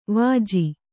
♪ vāji